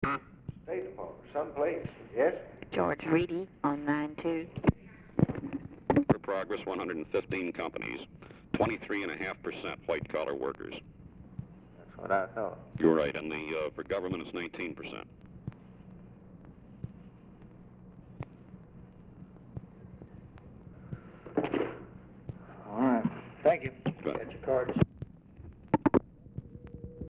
Telephone conversation # 1656, sound recording, LBJ and GEORGE REEDY, 1/30/1964, 11:30AM | Discover LBJ
Format Dictation belt
Oval Office or unknown location